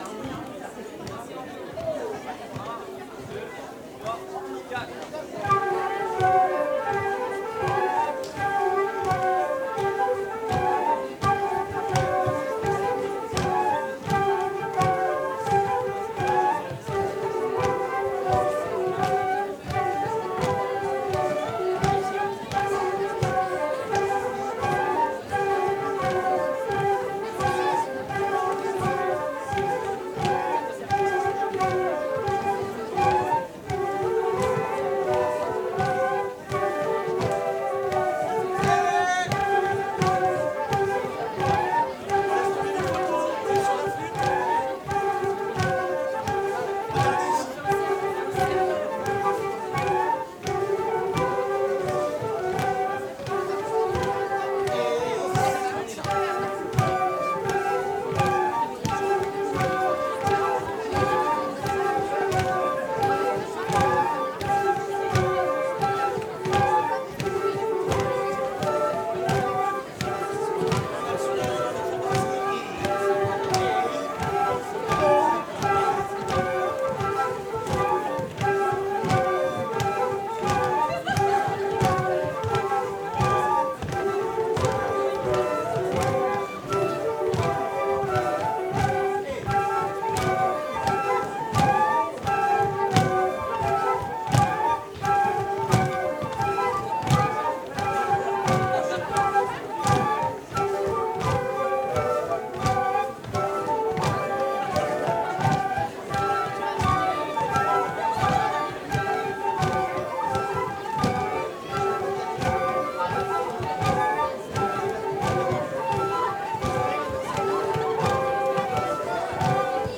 05_ronde_loudia-flutes.mp3